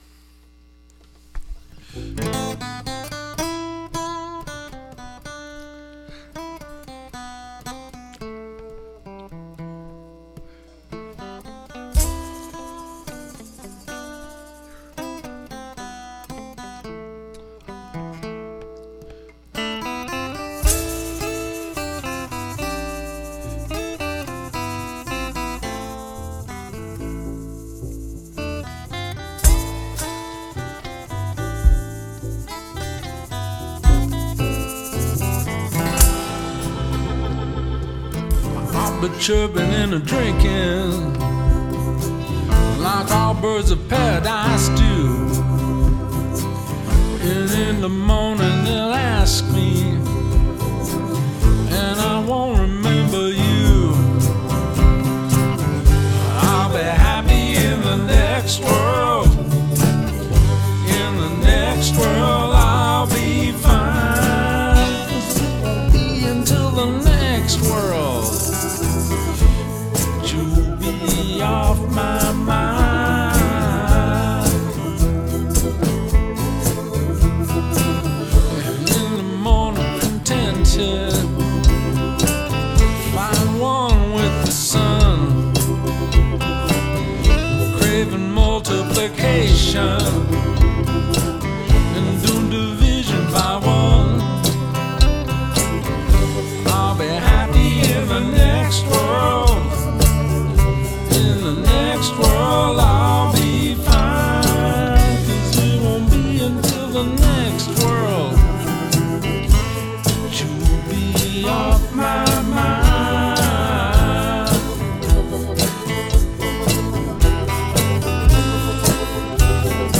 bass
acoustic guitar and vocal